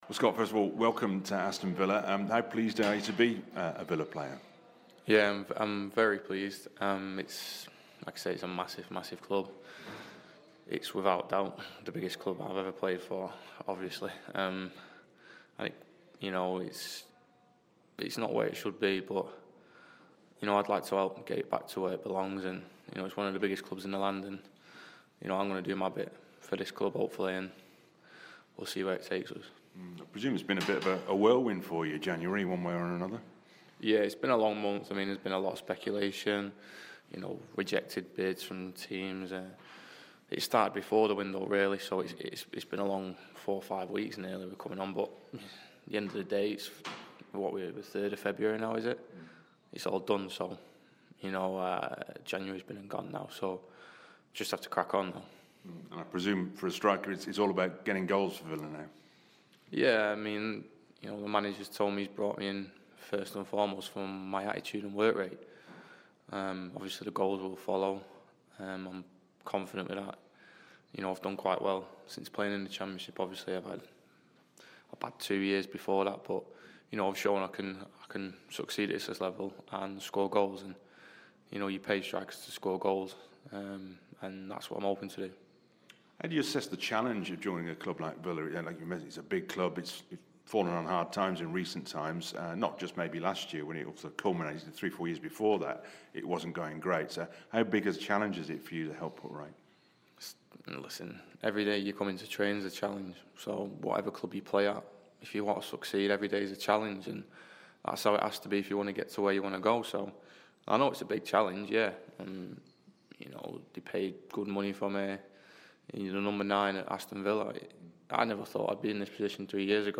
New Aston Villa forward Scott Hogan speaks to BBC WM following his move from Brentford...